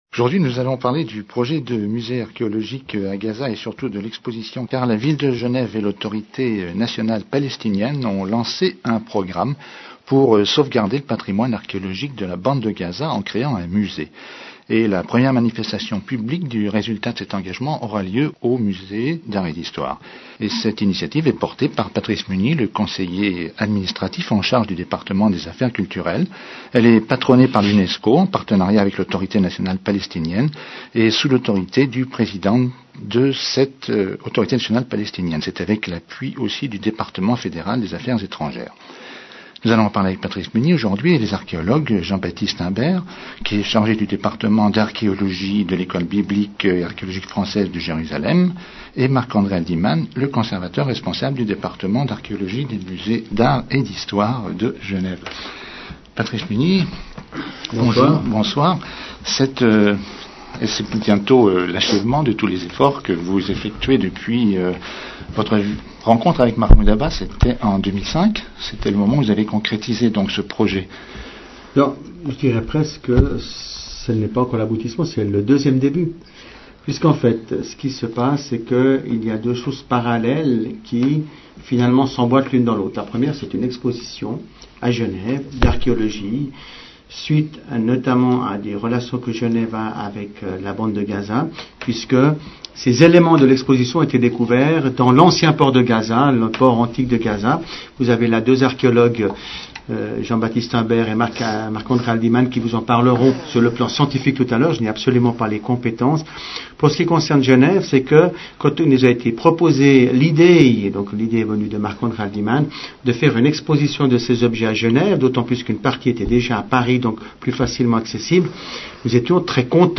Entretien avec Patrice Mugny